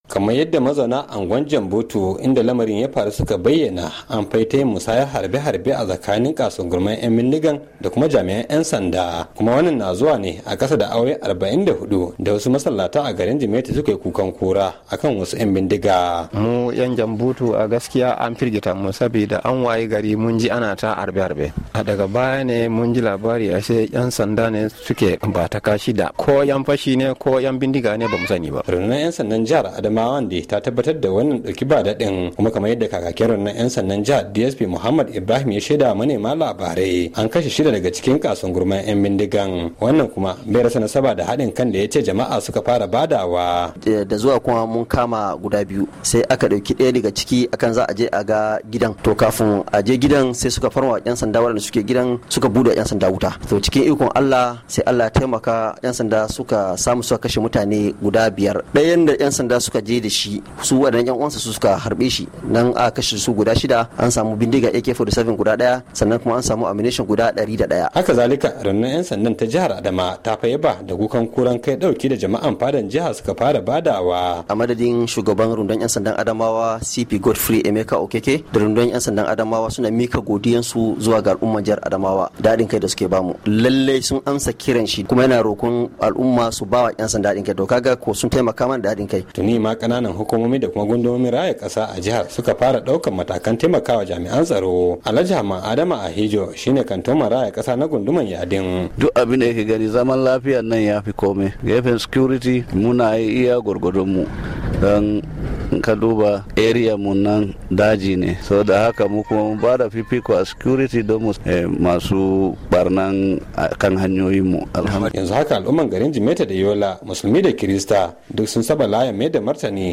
Rahoto dangane da kashe 'yan bindiga a jihar Adamawa - 2:21